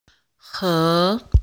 二声の単語
「河 hé」の発音